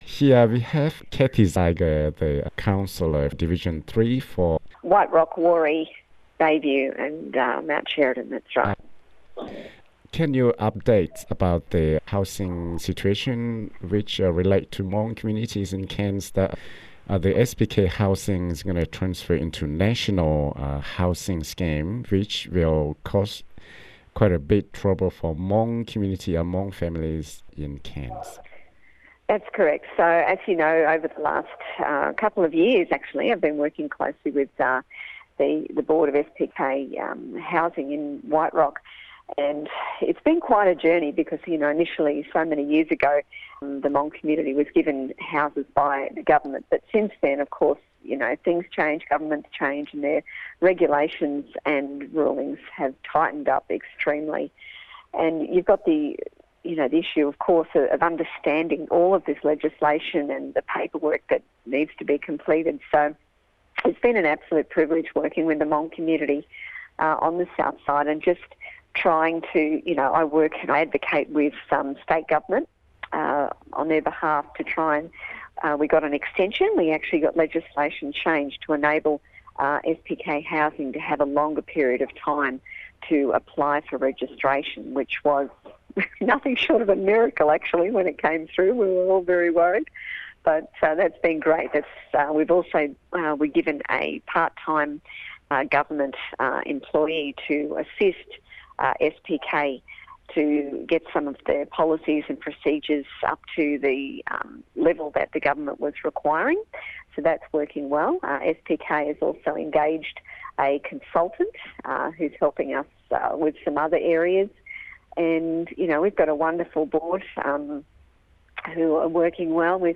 Cairns Councillor Division 3, Cathy Zeiger Source: Courtesy of Cathy Zeiger